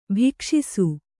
♪ bhikṣisu